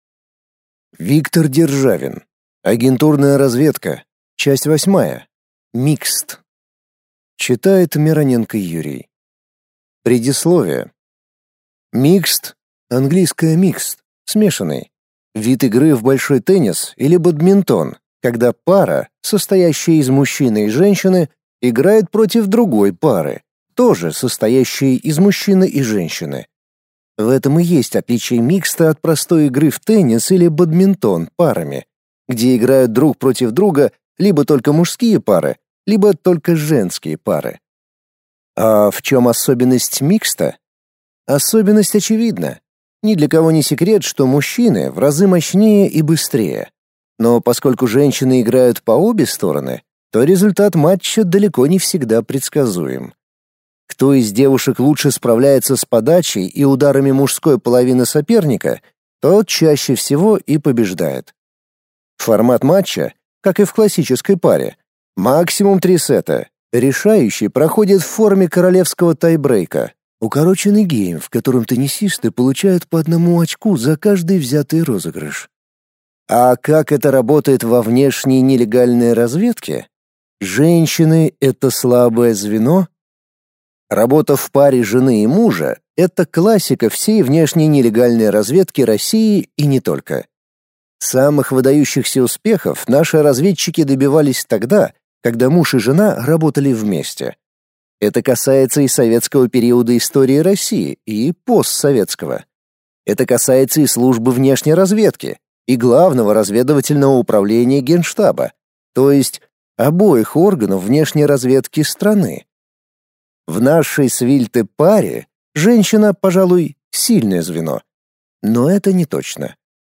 Аудиокнига Агентурная разведка. Часть 8. Микст | Библиотека аудиокниг